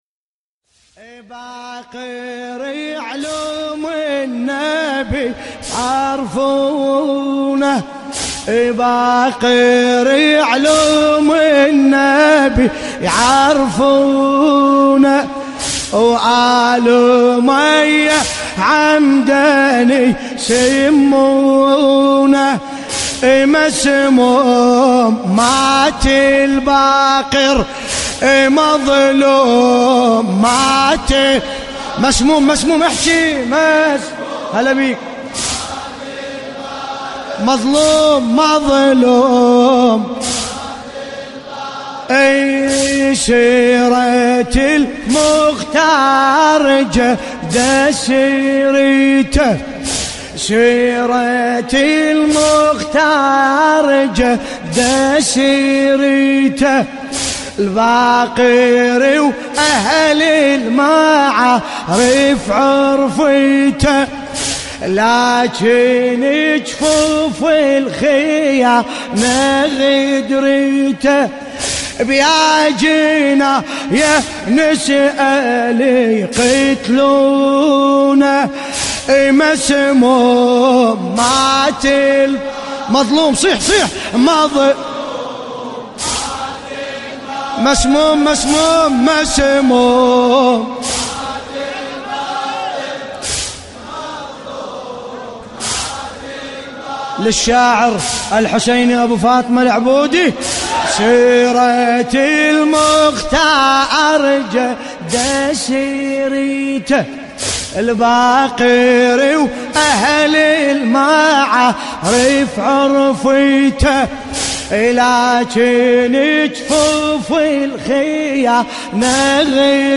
الرادود
المناسبة : شهادة الامام الباقر ع